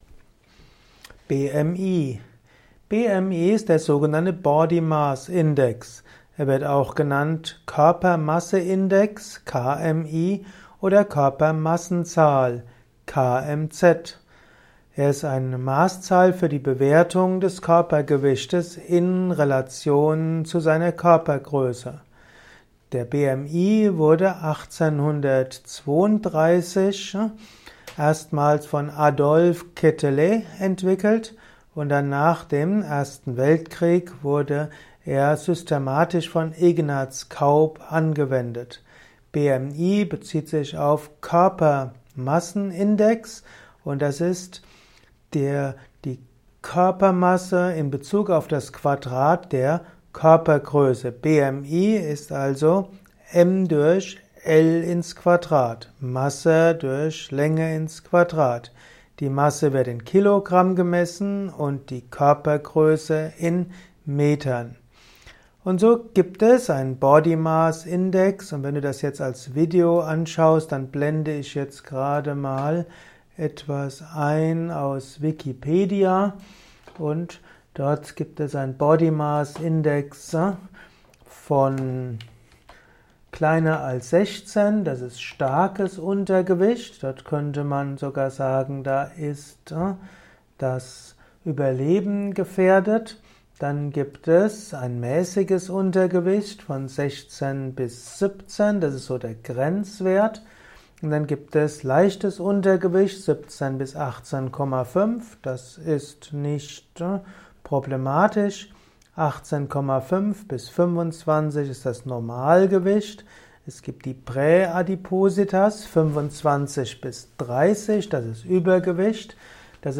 Kompakte Informationen zum Body-Maß-Index (BMI) in diesem Kurzvortrag